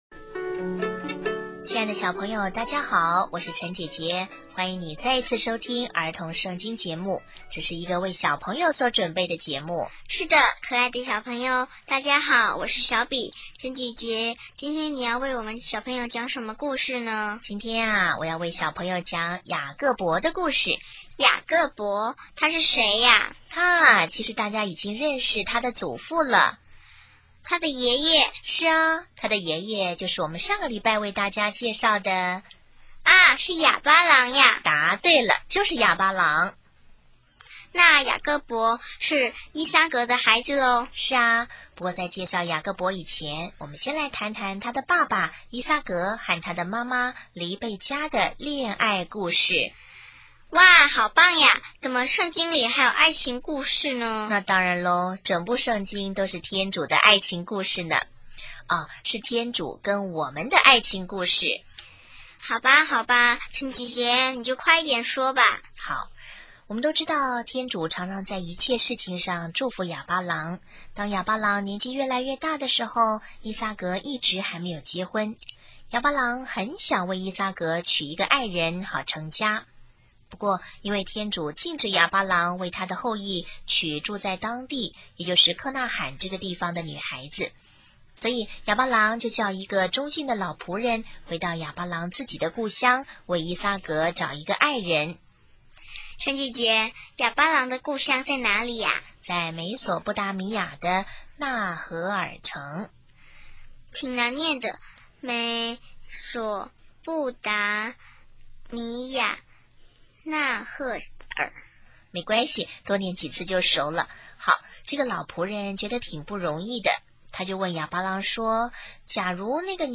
【儿童圣经故事】9|雅各伯(一)依撒格与黎贝加爱的故事